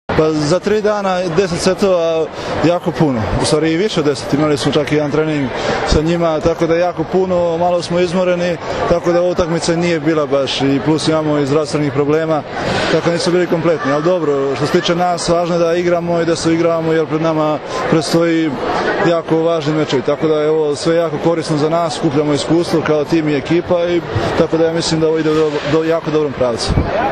IZJAVA MARKA IVOVIĆA